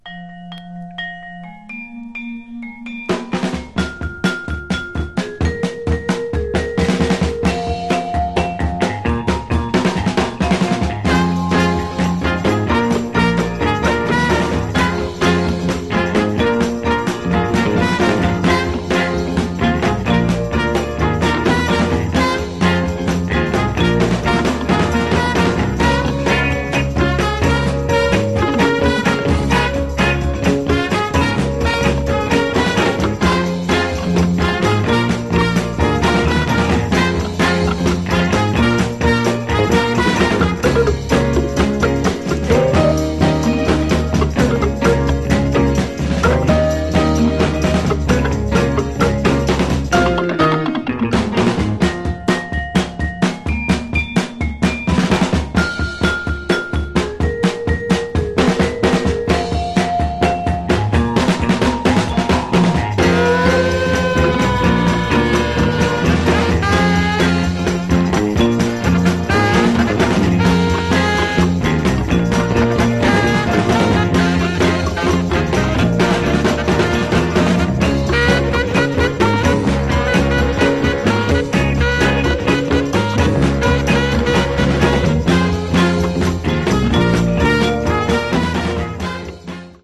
Genre: Soul Instrumentals